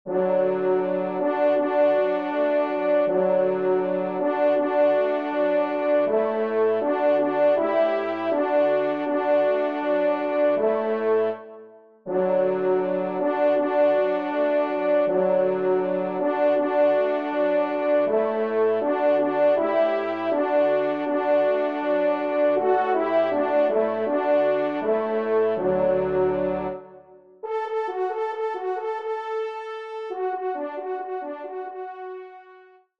Genre :  Divertissement pour Trompes ou Cors
Pupitre 2° Cor